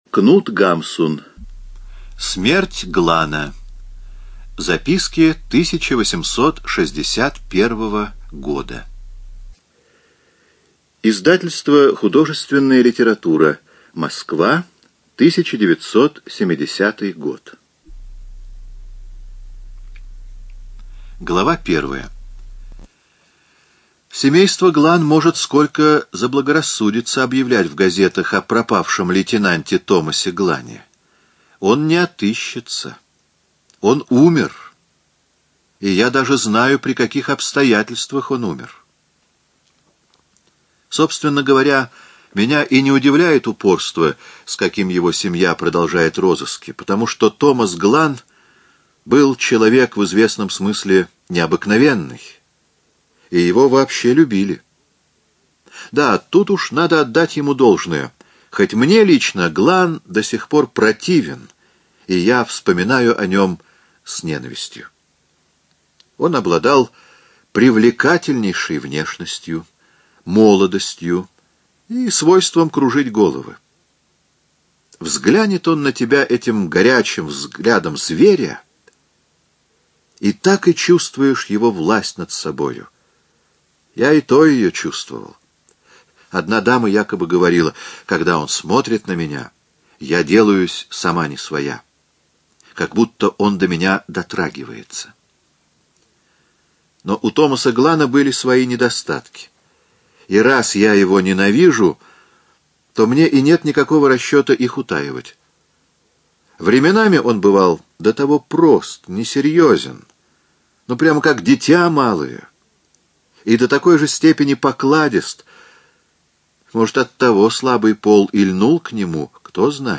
ЖанрКлассическая проза
Студия звукозаписиЛогосвос